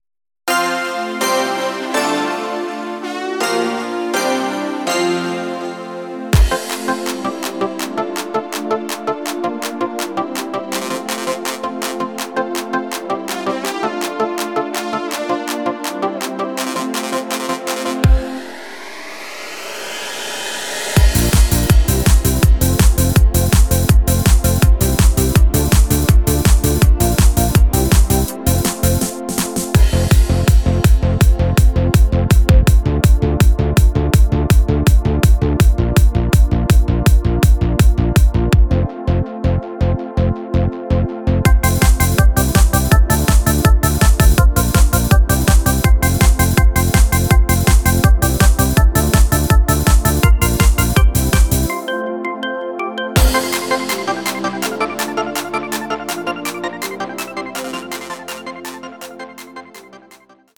flockiger Song